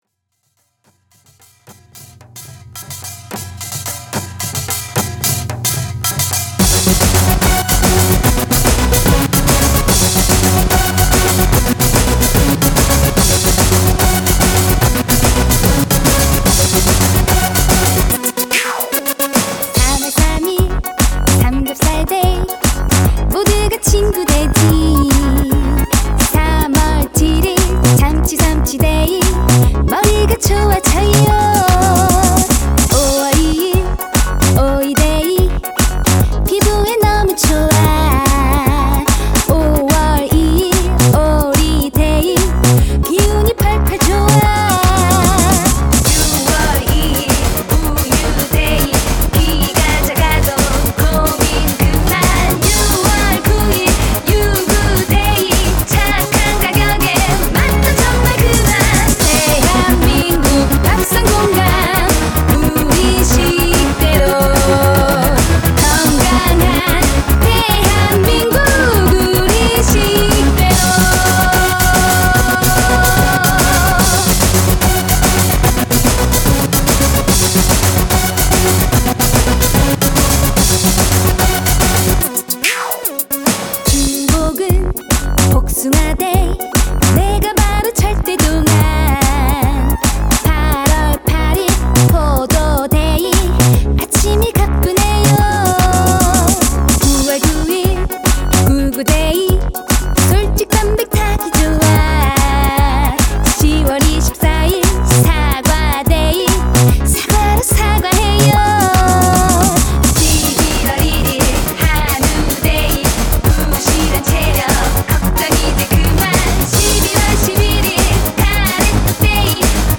신나고 재미있는